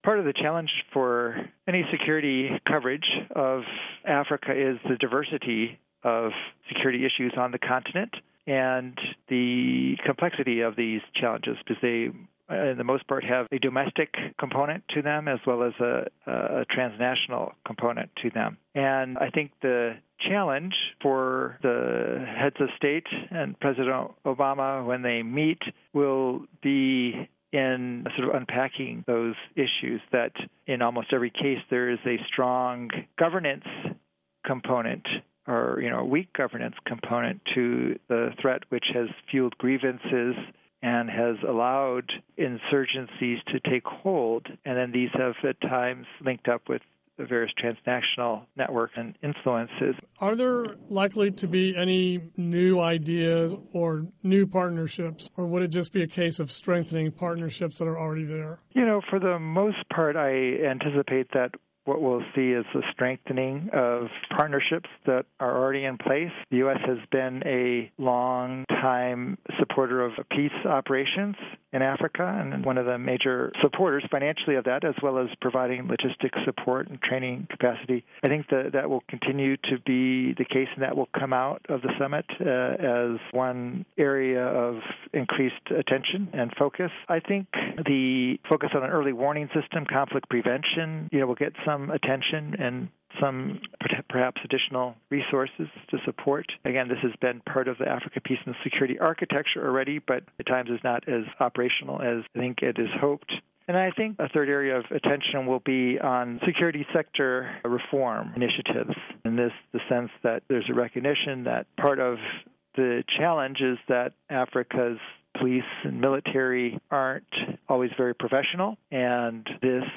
Listen to report on US-Africa summit